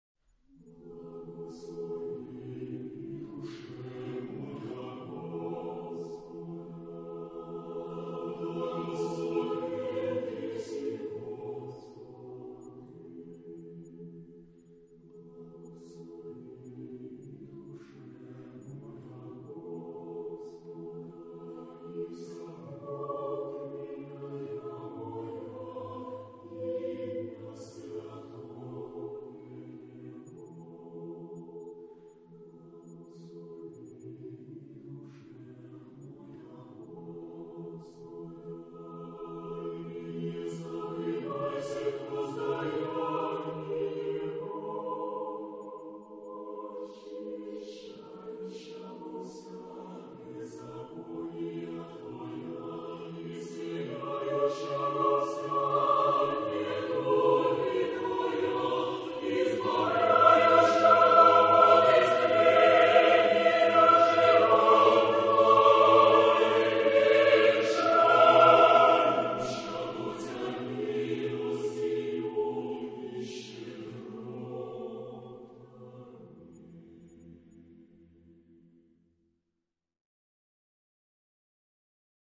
SATB (4 voices mixed).
Sacred.
Type of Choir: SATB (4 mixed voices )